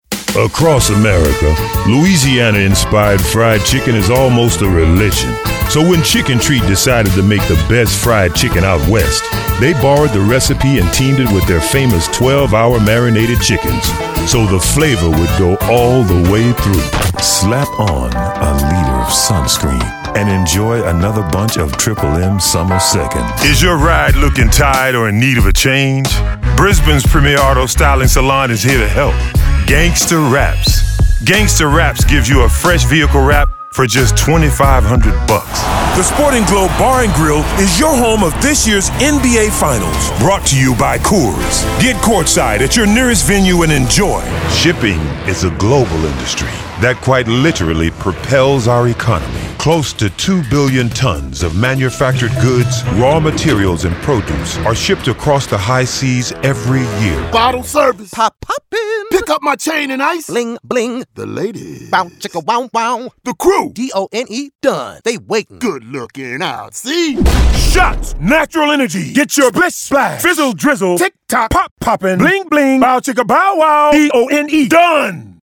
Compilation